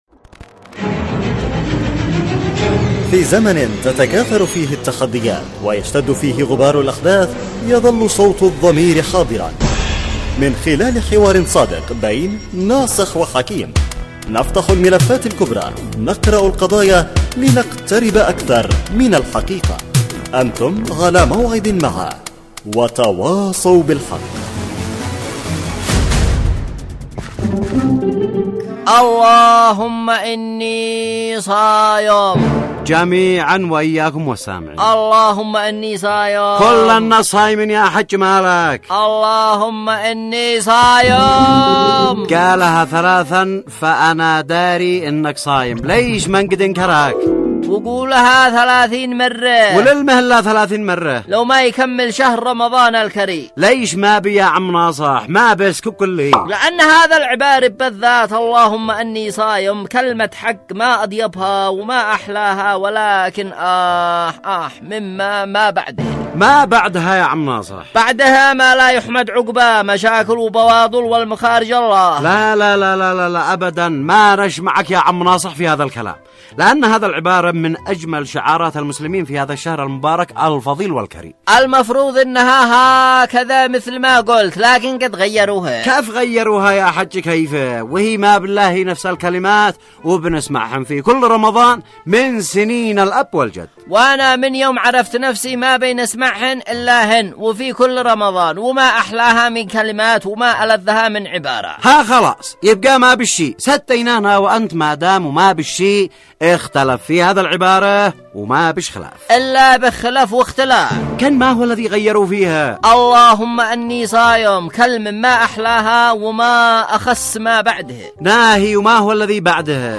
وتواصوا بالحق، برنامج إذاعي درامي يعمل كل يوم على طرح إشكالية و مناقشة مشكلة تهم الجميع وبعد جدال بين الطرفين يتم الاحتكام بينهم الى العودة الى مقطوعة للسيد القائد تعالج المشكلة